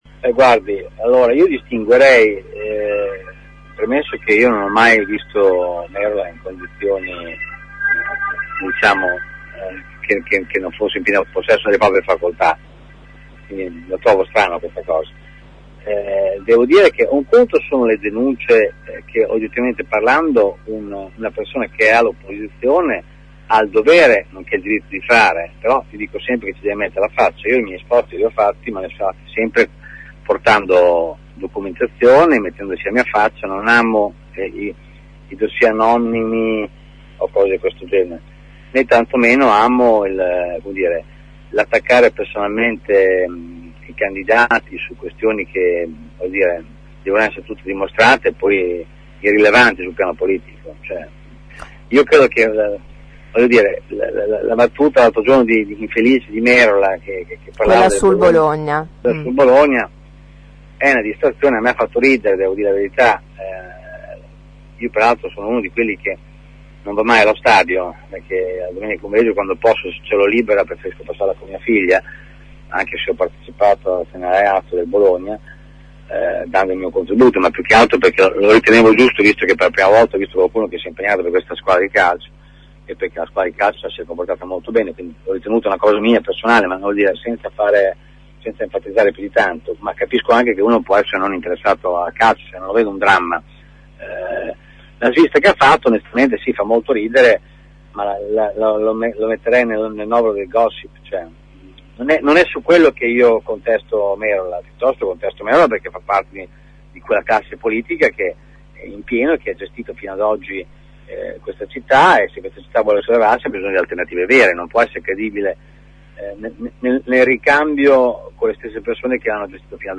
Così ai nostri microfoni Enzo Raisi di Fli cui abbiamo chiesto di commentare l’attacco dell’ex assessore Antonio Amorosi al candidato del centro sinistra Virginio Merola.